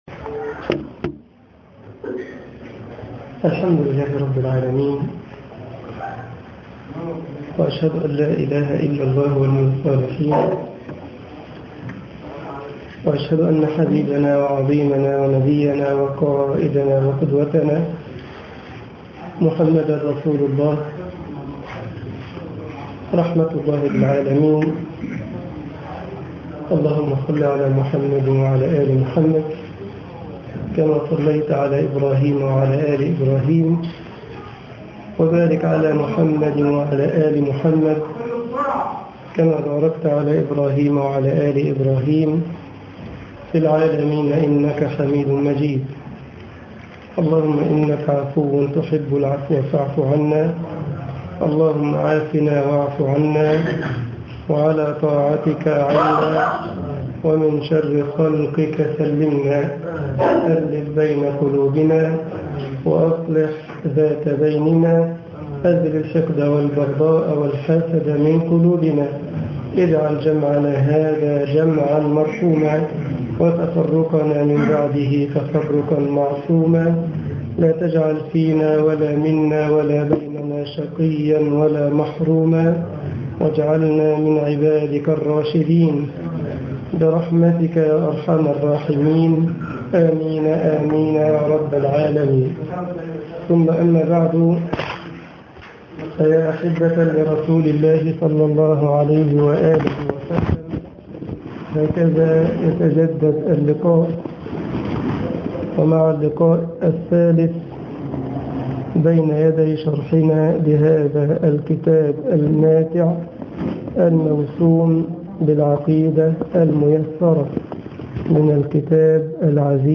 العقيدة الميسرة 3 طباعة البريد الإلكتروني التفاصيل كتب بواسطة: admin المجموعة: العقيدة الميسرة Download مسجد النصر ــ الشرابية ــ القاهرة التفاصيل نشر بتاريخ: الثلاثاء، 13 نيسان/أبريل 2010 23:32 الزيارات: 4163 التالي